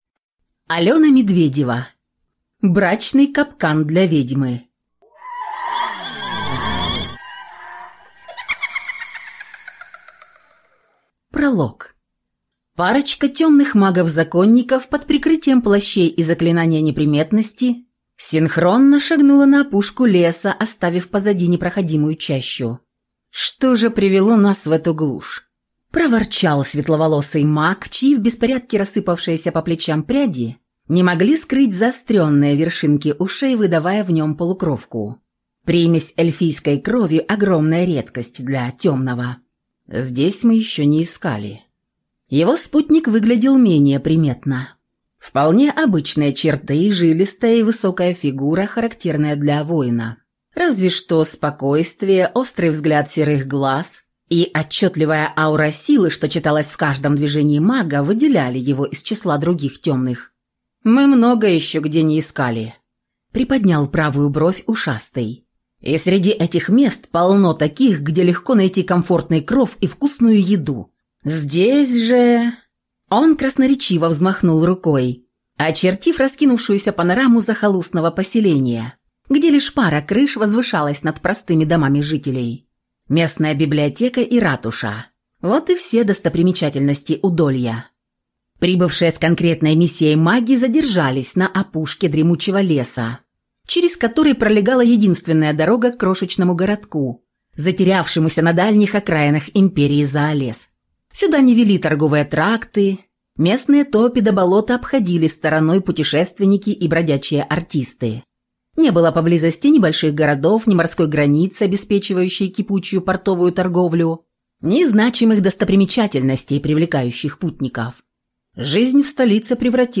Аудиокнига Брачный капкан для ведьмы | Библиотека аудиокниг